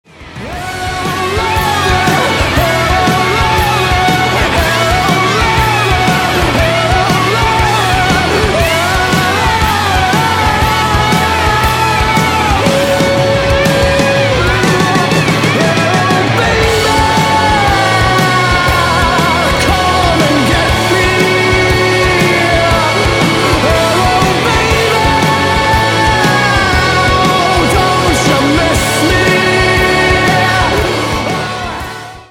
электрогитара